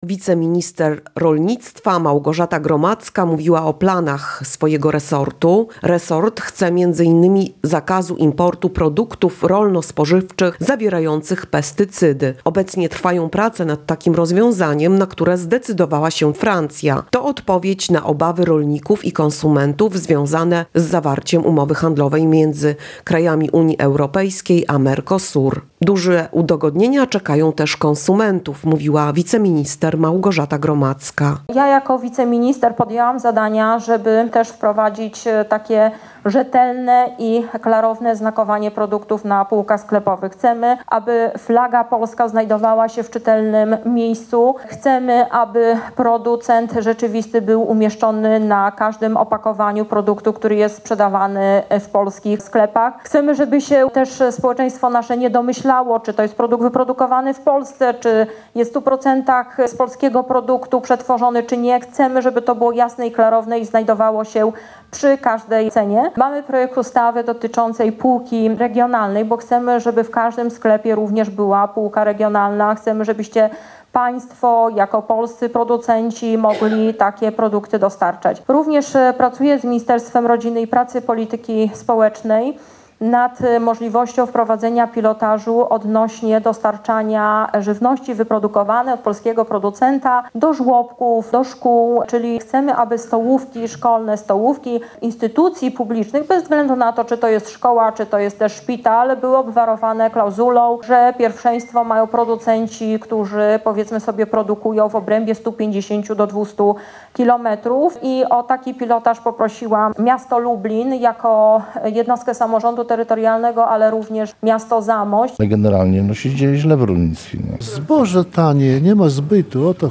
Problemy ze skupem zboża, konieczność odbudowy hodowli trzody chlewnej a także obawy rolników związane z wprowadzeniem umowy handlowej między krajami Unii Europejskiej a Mercosur – to między innymi tematy poruszane na spotkaniu rolników w Janowie Podlaskim z wiceminister rolnictwa i rozwoju wsi Małgorzatą Gromadzką.